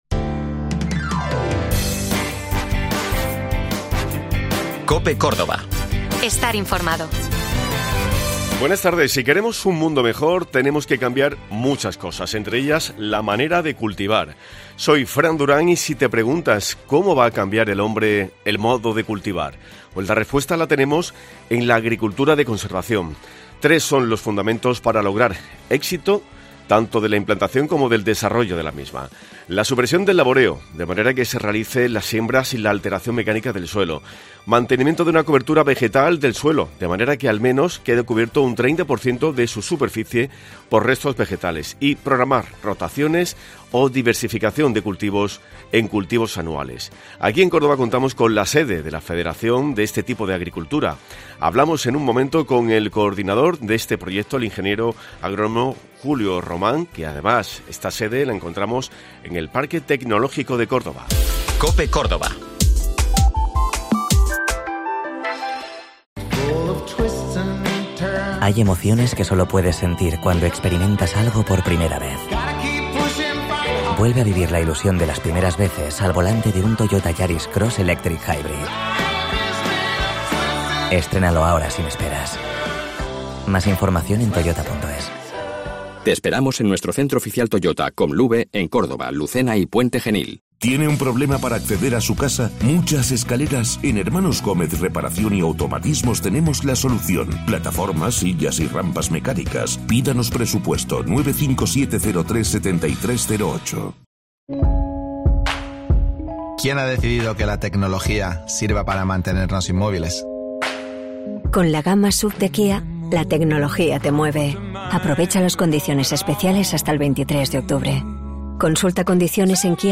Un repaso diario a la actualidad y a los temas que te preocupan.